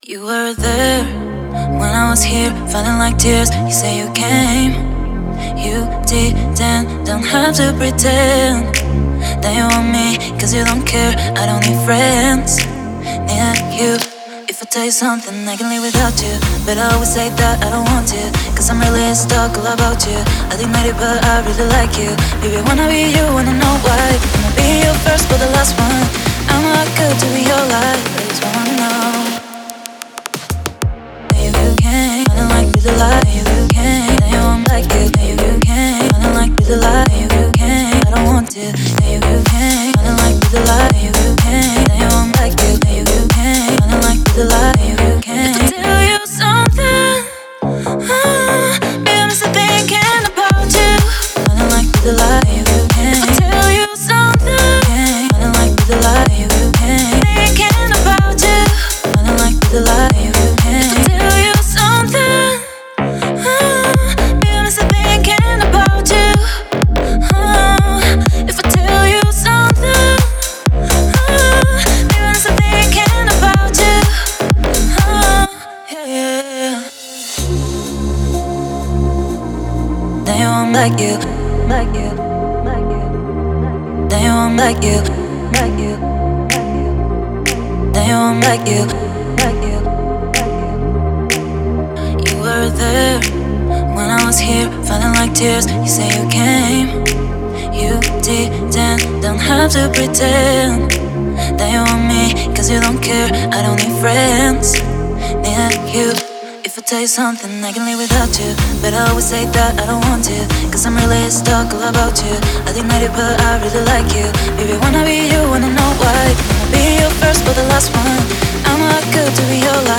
энергичная поп-песня